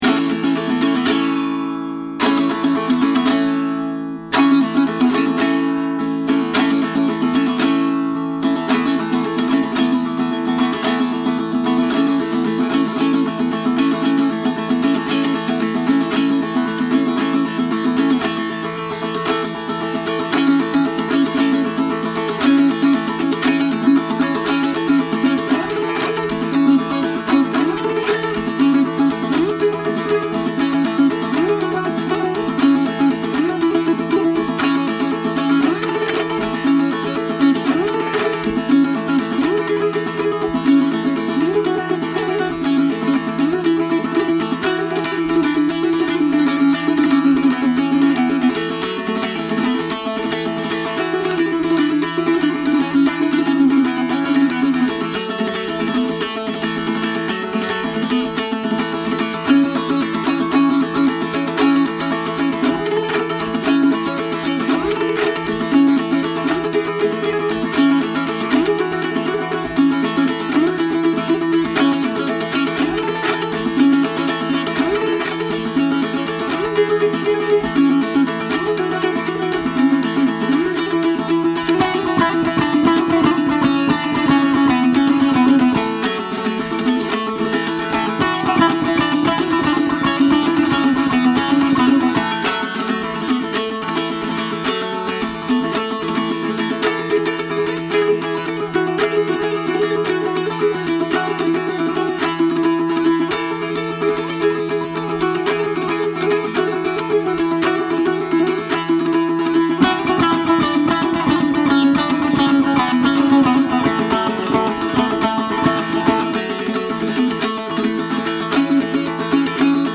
弦楽器タンブールによる演奏